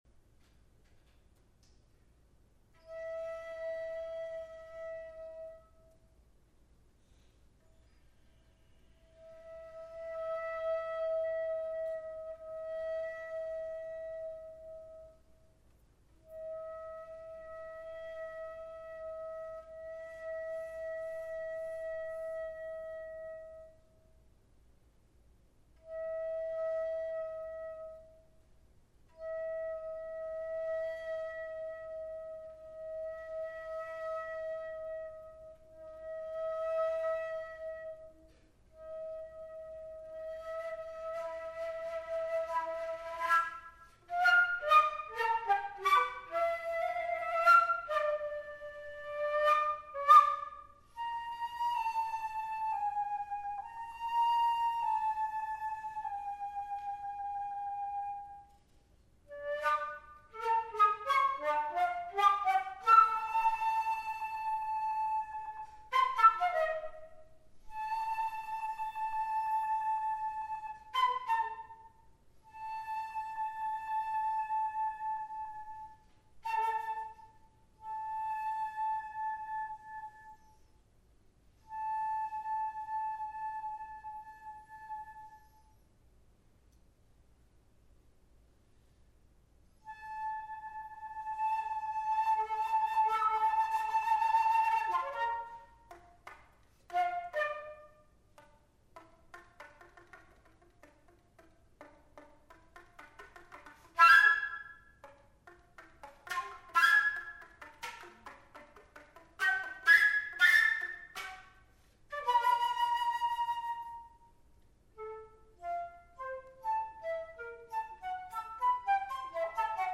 Emptiness (flute solo)
The sense of emptiness is being presented in this flute solo and experimental extended flute techniques are widely used. Being premiered at Wolfe Recital Hall at Del Mar College by myself, Emptiness is the very first composition of mine in my career as a composer.